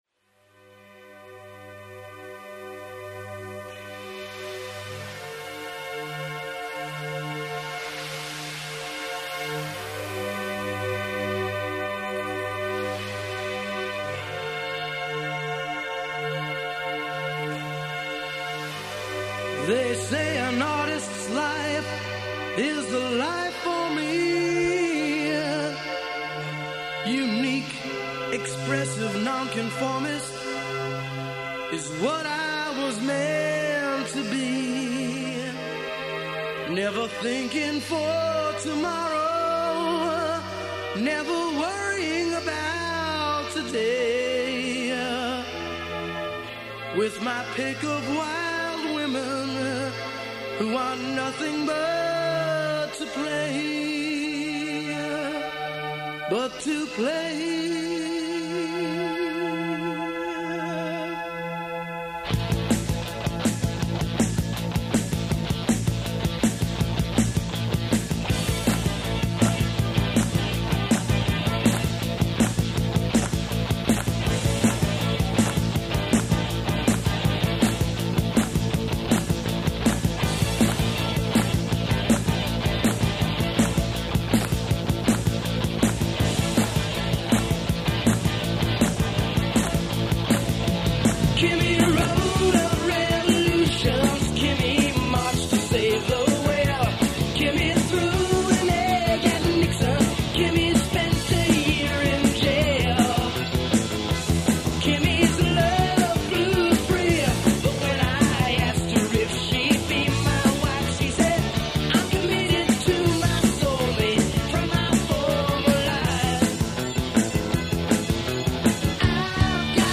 Pretty Little CPA (New Age)*